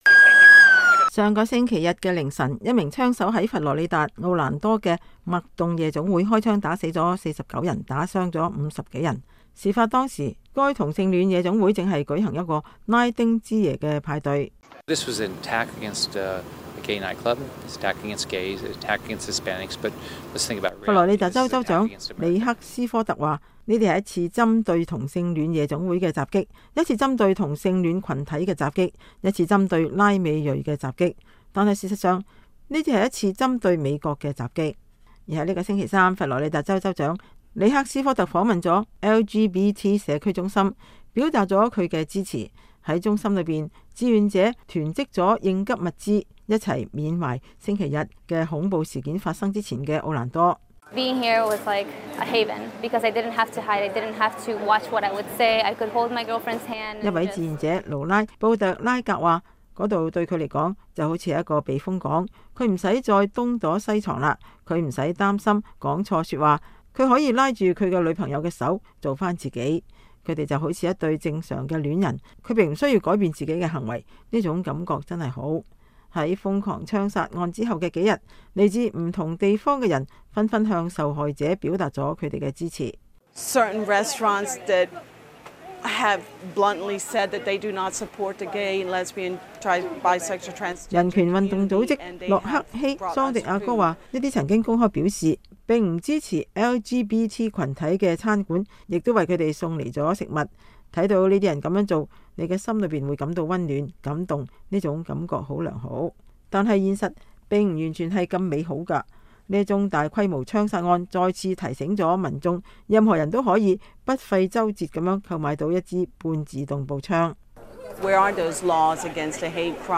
本周三，美國之音記者采訪了這場恐怖襲擊的倖存者。